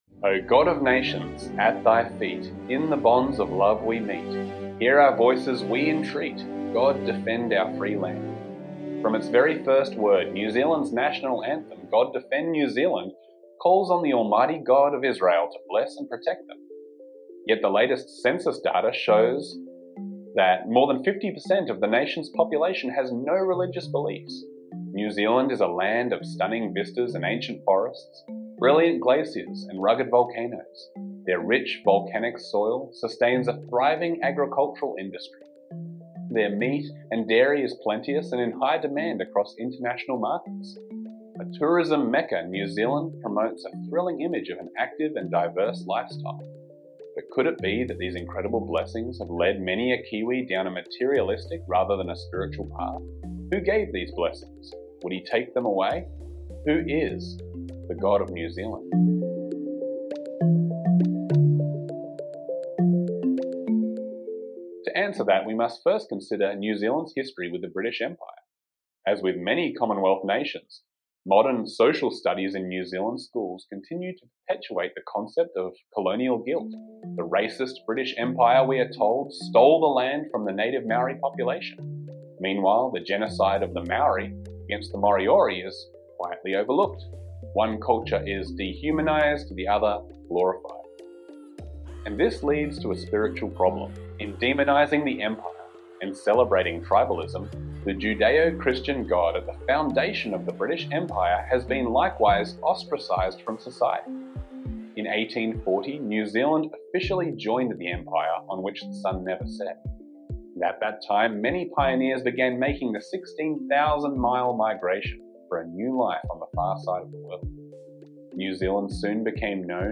Join the discussion as Trumpet staff members compare recent news with Bible prophecy.